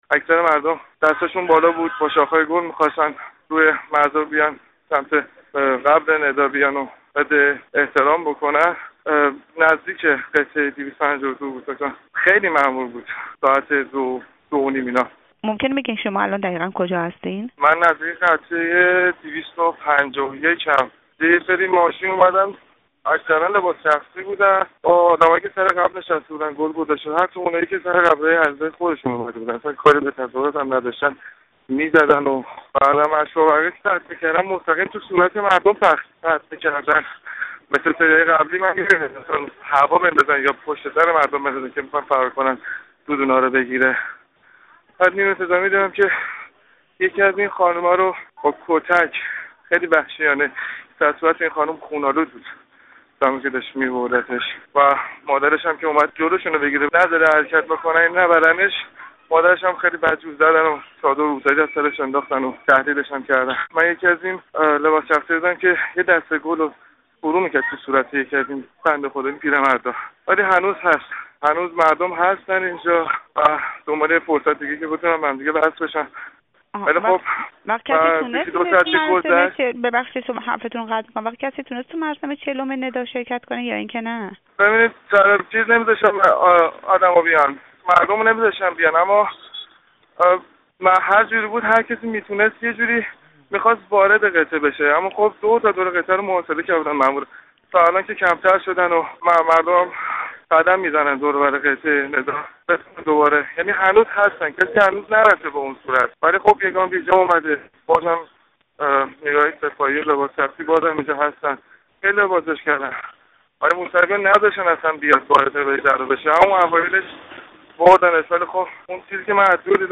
شاهد عینی تجمع بهشت زهرا، پنجشنبه هشت مرداد ماه